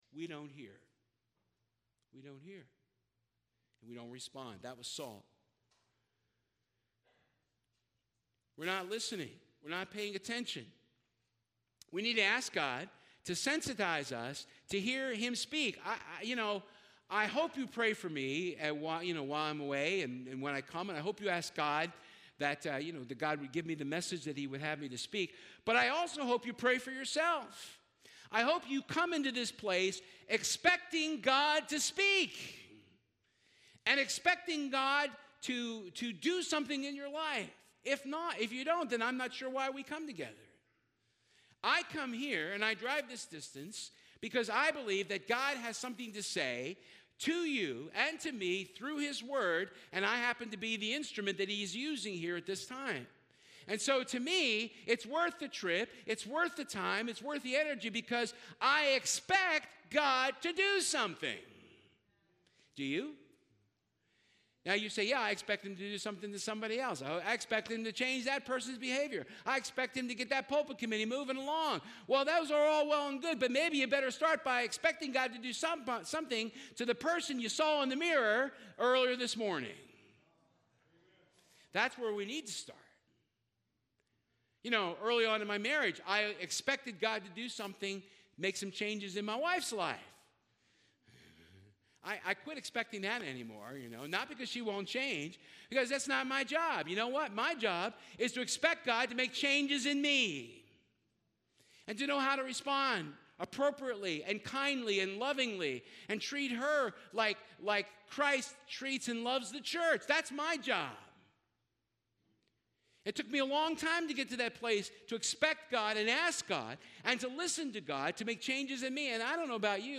Acts 9:1 Service Type: Sunday Service Communication